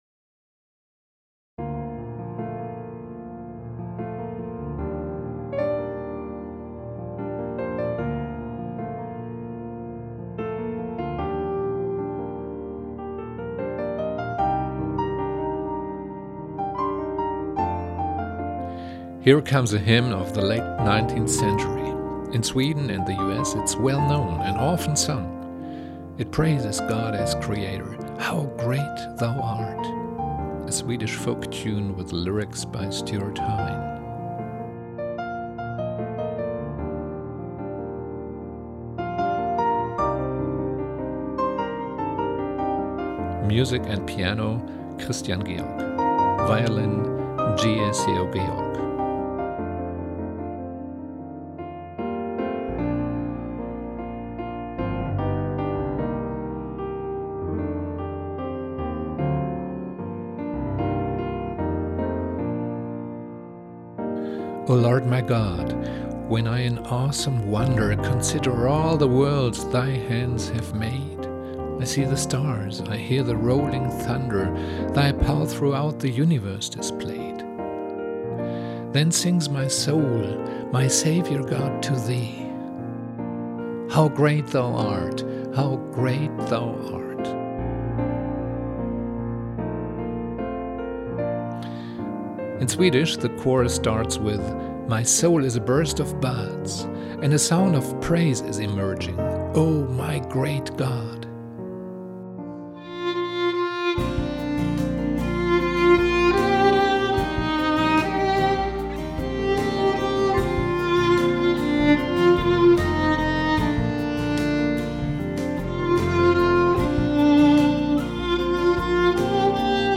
Piano & Arrangement
Violin